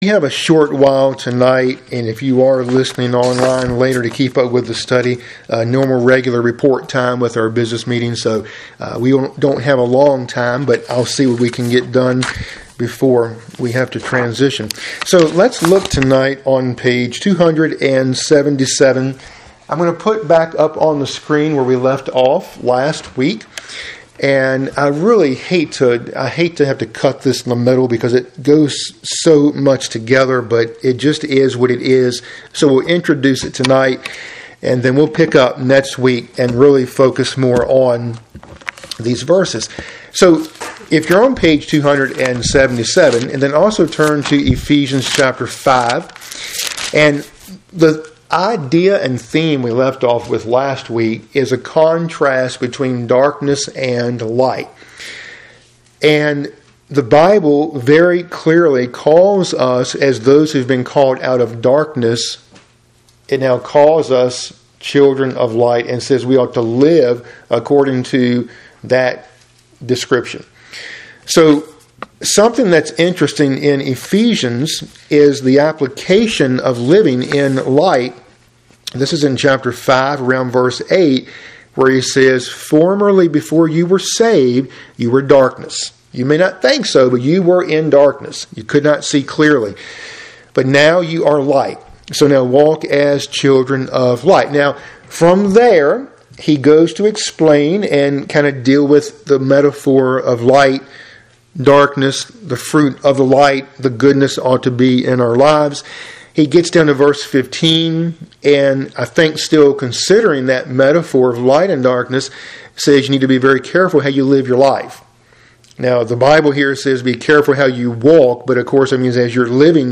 Midweek Bible Study – Lesson 56 (cont.)